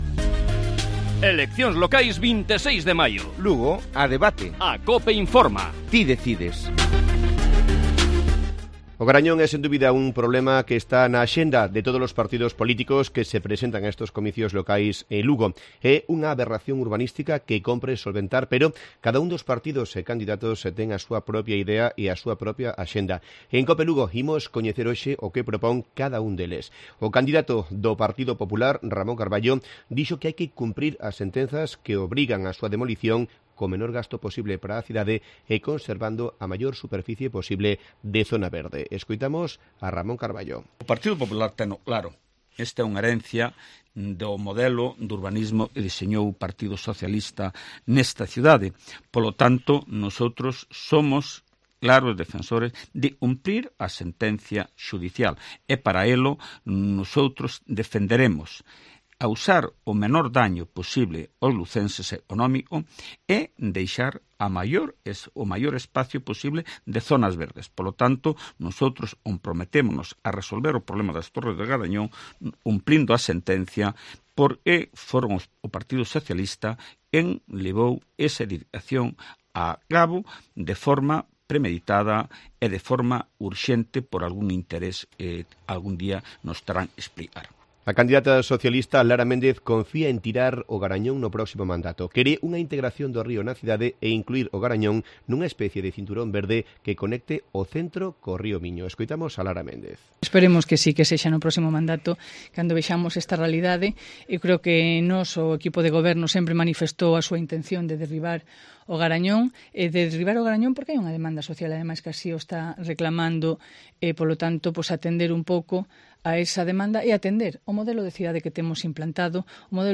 Lugo a debate: Los candidatos hablan sobre el futuro de O Garañón